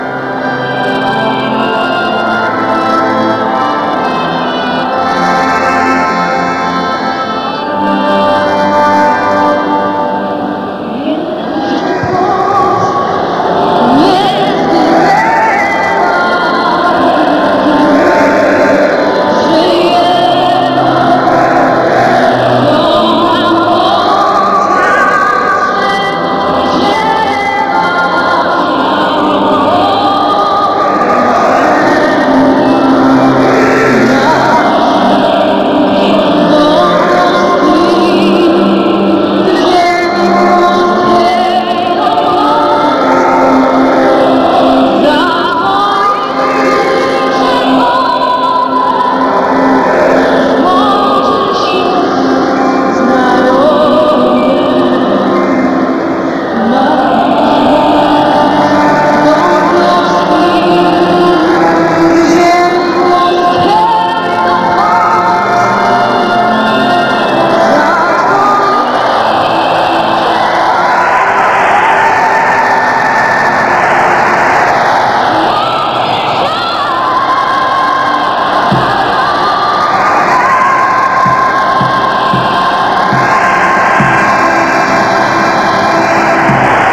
aaa i nie wiem moze ktos juz podal link do "pelnej" wersji wsytepu pani Gorniak ze stadionu w Korei przed meczem naszej reprezentacji..
gorniak_hymn.mp3